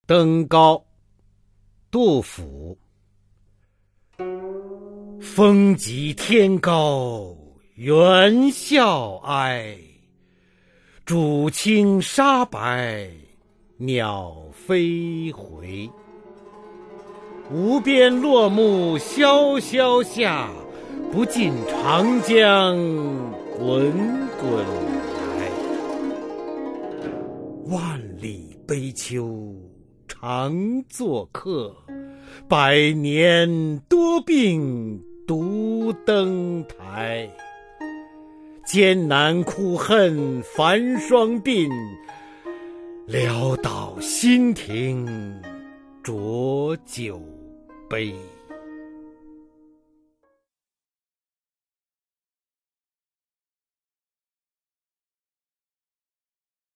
[隋唐诗词诵读]杜甫-登高（男） 唐诗朗诵